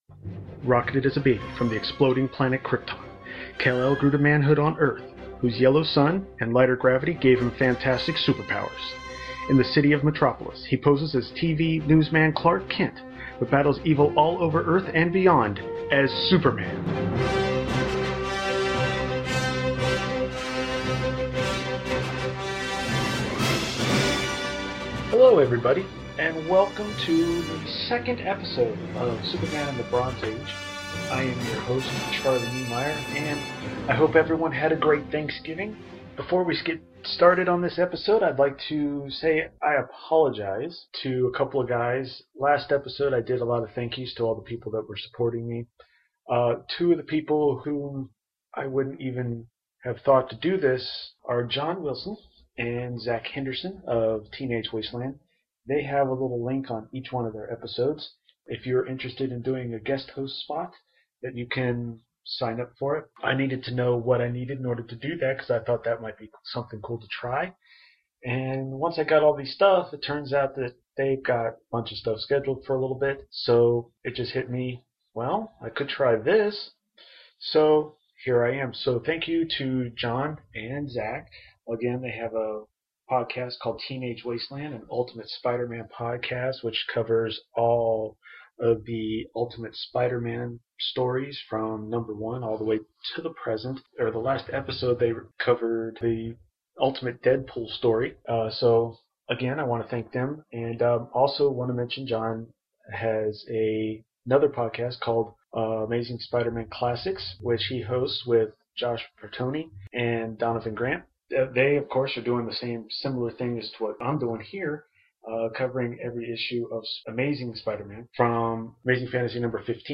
In this episode, some apologies, the first piece of listener mail and reviews of Worlds Finest #198 and Action Comics #394. Again, I apologize for any audio problems.